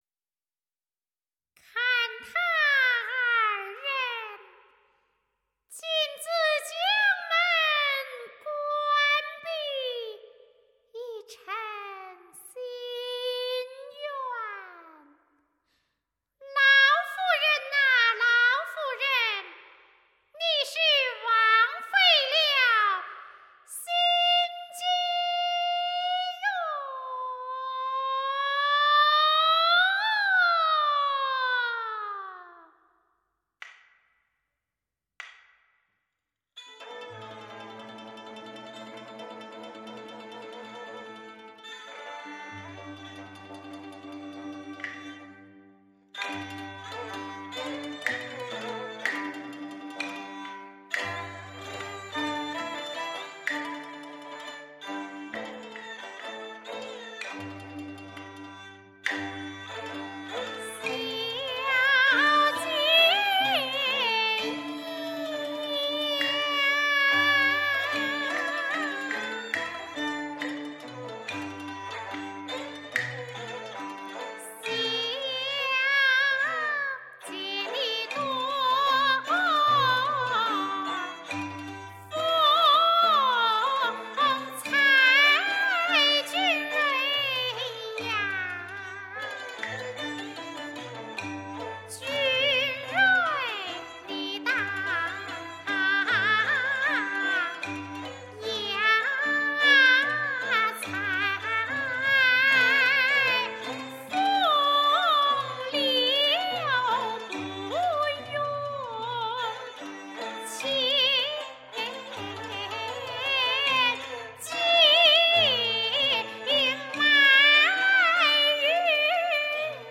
京剧
录音棚：上海广电录音棚 中唱录音棚